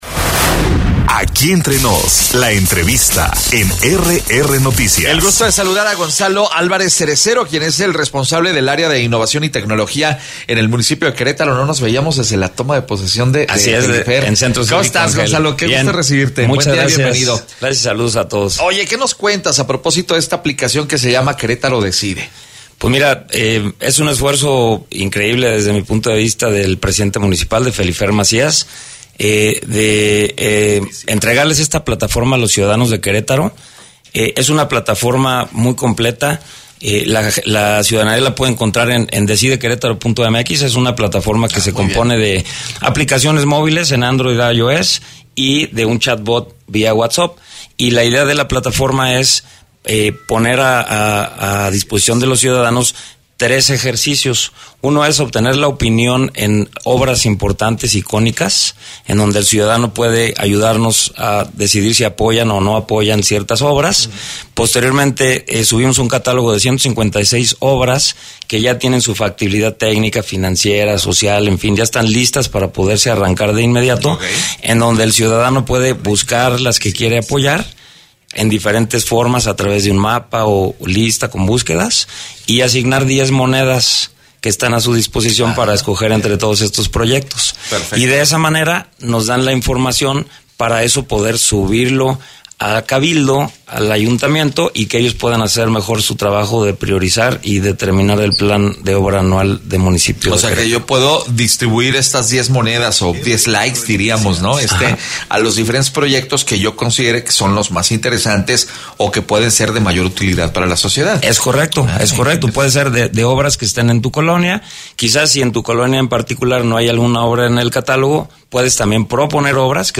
EntrevistasMunicipiosPodcast